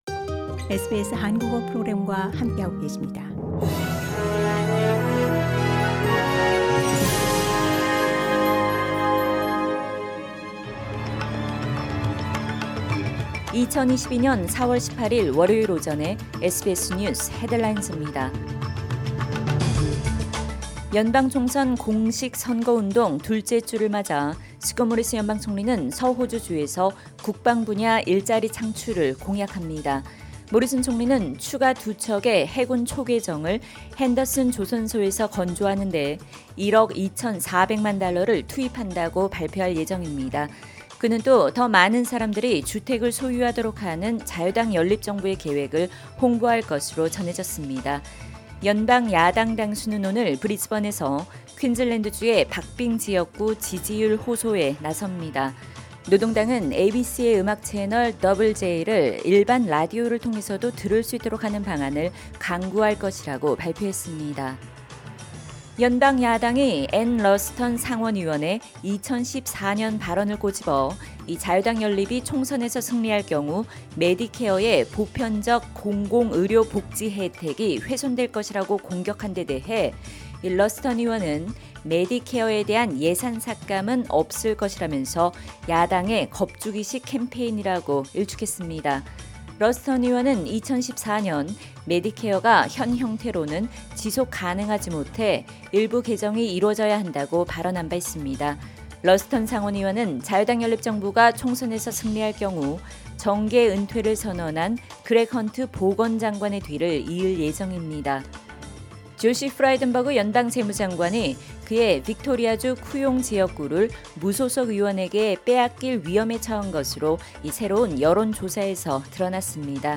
SBS News Headlines…2022년 4월 18일 월요일 오전 뉴스
2022년 4월 18일 월요일 오전 SBS 뉴스 헤드라인즈입니다.